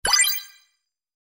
游戏点击音效.mp3